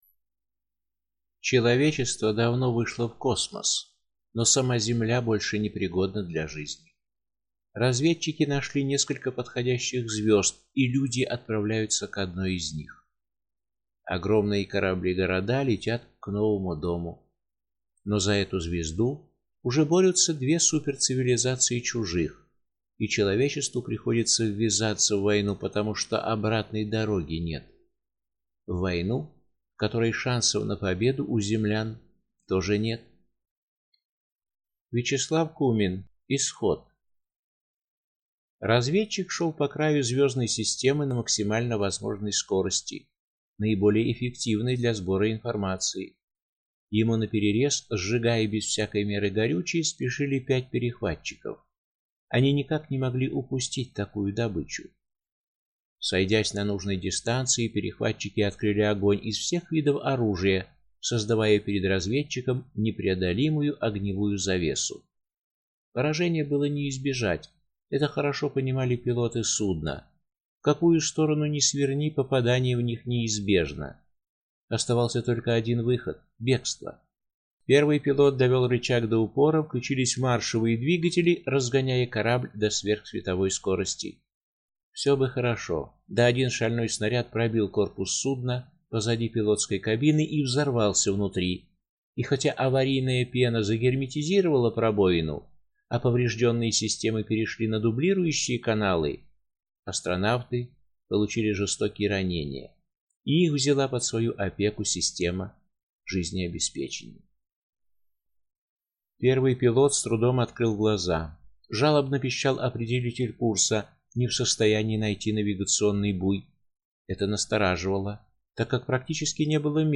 Аудиокнига Исход | Библиотека аудиокниг
Прослушать и бесплатно скачать фрагмент аудиокниги